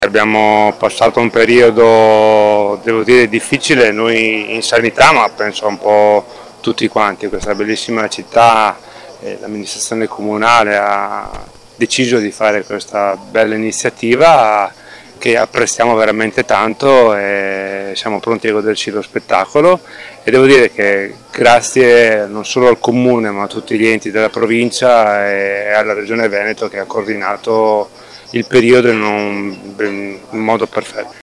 Al nostro microfono anche: